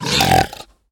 sounds / mob / piglin / death3.ogg
death3.ogg